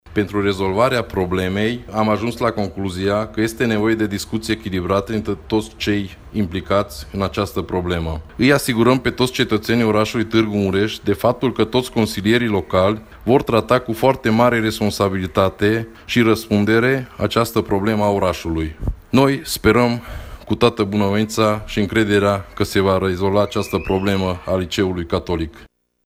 Potrivit consilierului local UDMR, Magyari Előd, au fost discutate toate aspectele legale și neconcordanțele legate de această situație, care afectează mii de absolvenți de clasa a VIII-a, iar acesta dă asigurări că membrii CL vor trata cu mare responsabilitate această problemă: